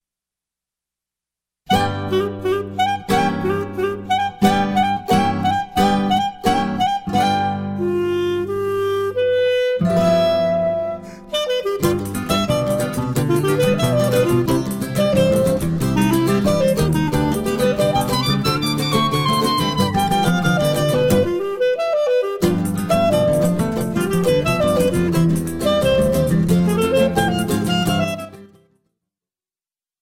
Choro ensemble